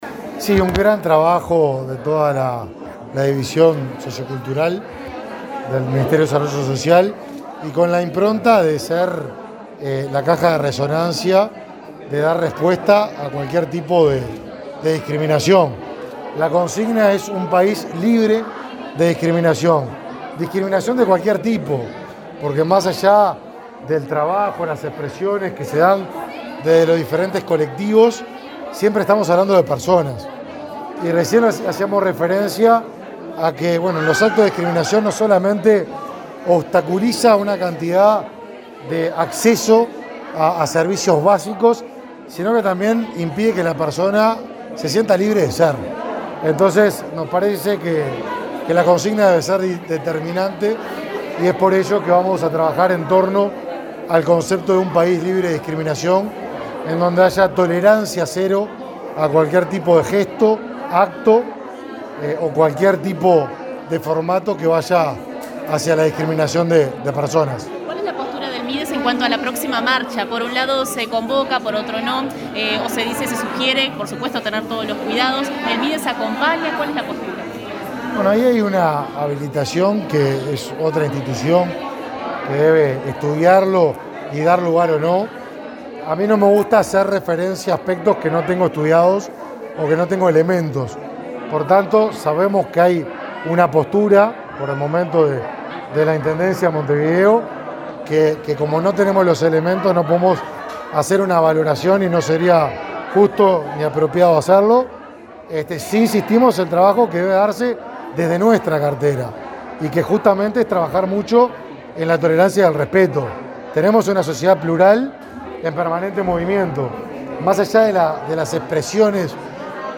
Declaraciones a la prensa del ministro de Desarrollo Social, Martín Lema